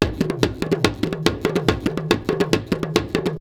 PERC 20.AI.wav